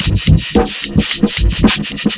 ELECTRO 11-L.wav